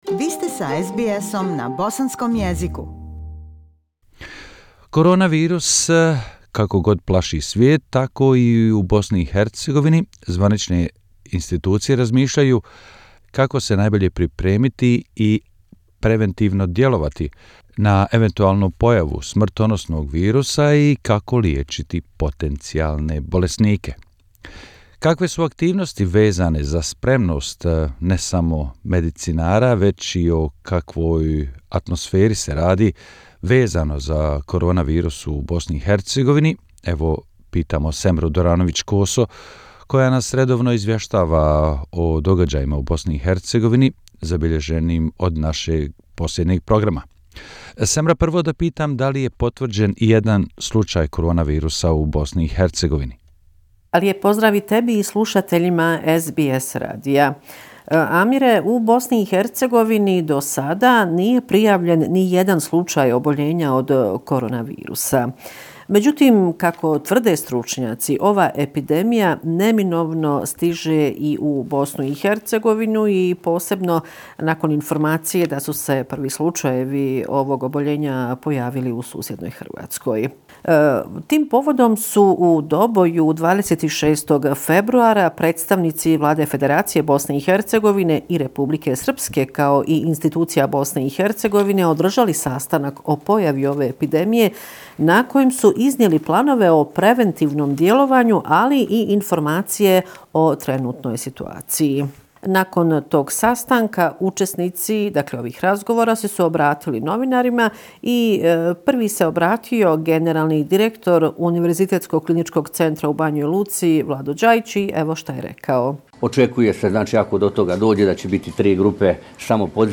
Izvještaj iz Bosne i Hercegovine o značajnijim događajima u posljednjih sedam dana.